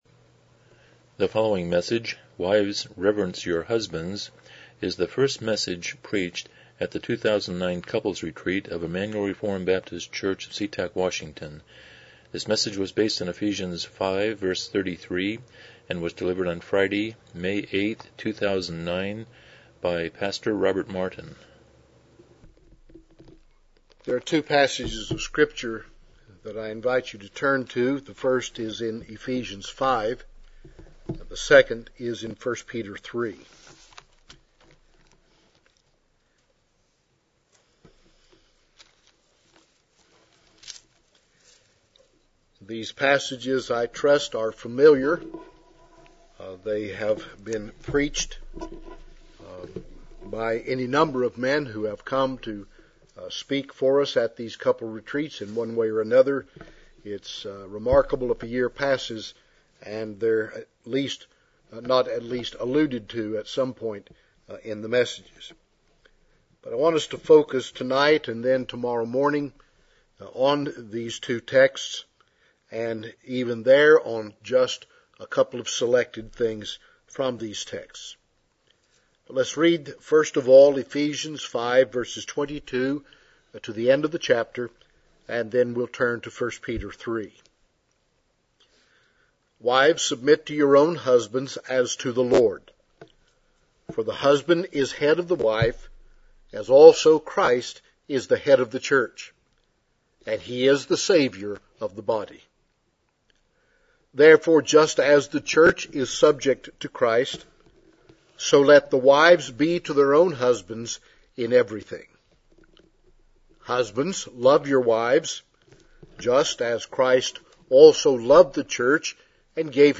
Passage: Ephesians 5:33 Service Type: Special Event